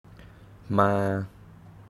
Tone: flat, steady
ToneMidLowFallingHighRising
Phoneticmaamàamâamáamǎa